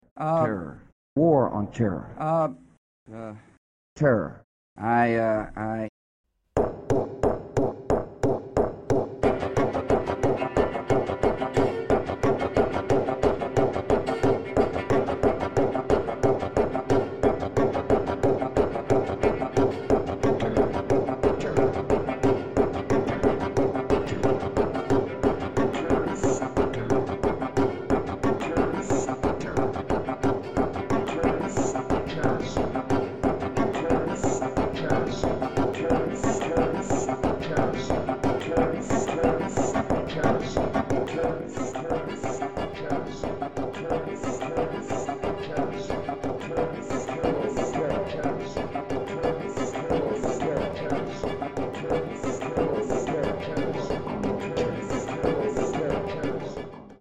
Retuned electric viola or cello and CD Duration: 7 min.